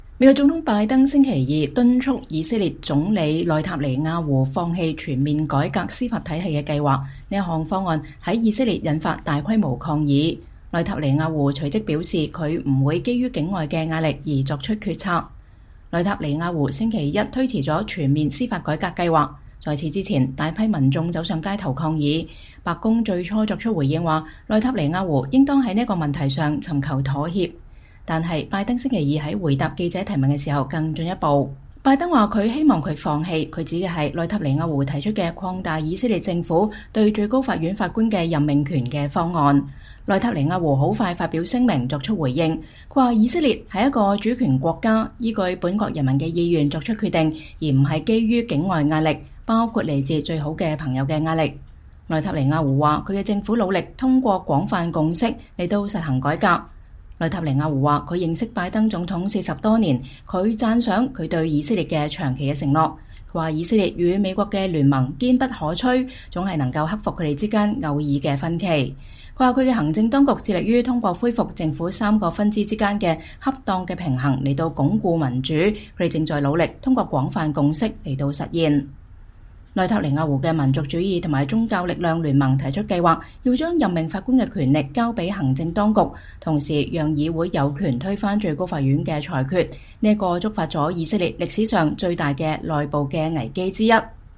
美國總統拜登在乘“空軍一號”專機從北卡羅萊納州返回華盛頓之前與記者交談。